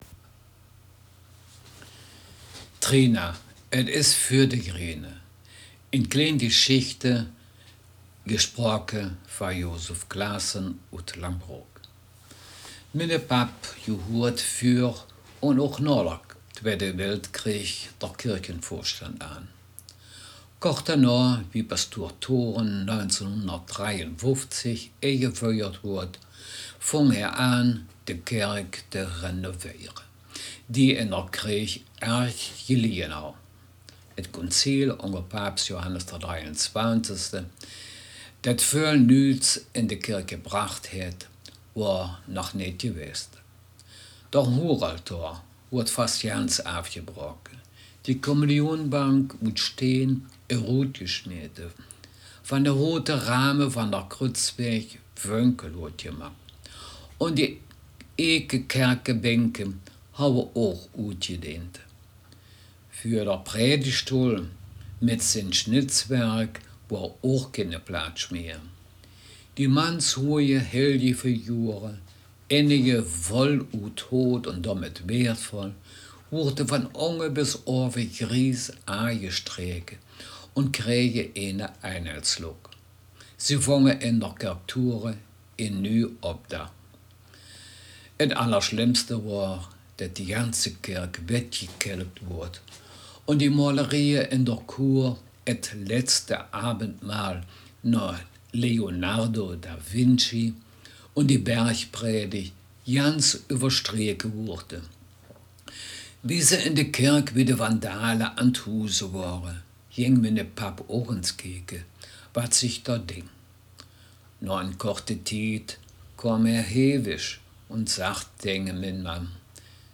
Text Mundart